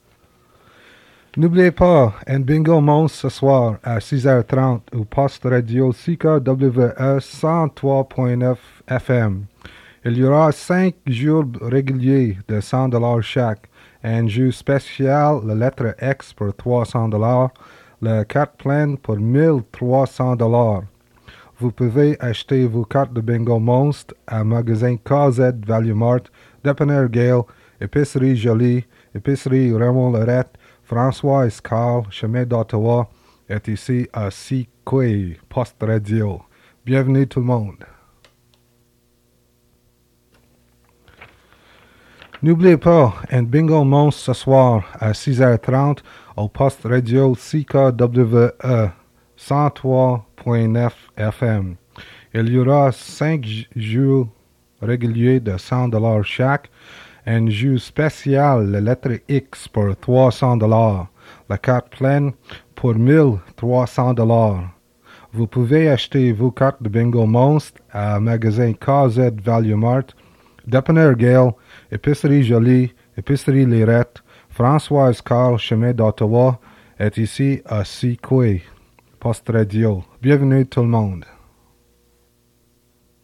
Fait partie de Bingo announcement